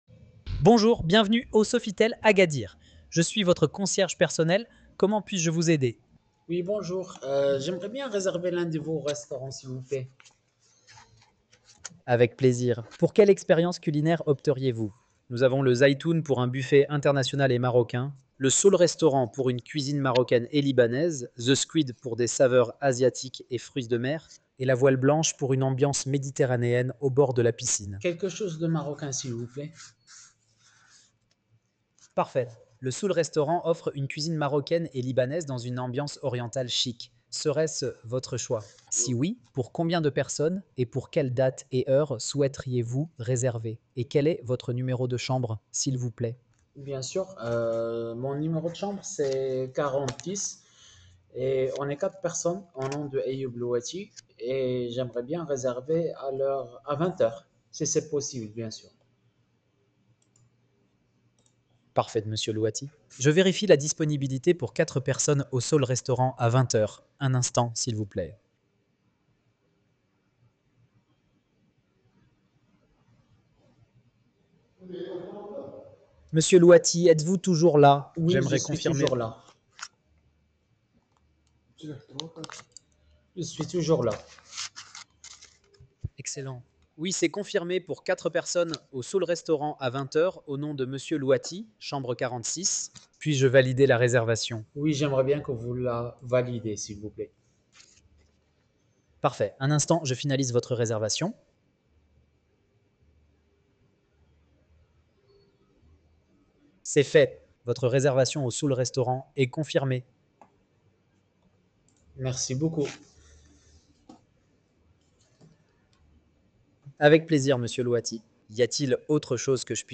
Écoutez notre IA.
Une réservation complète avec vente additionnelle, gérée à 100% par l’Intelligence Artificielle.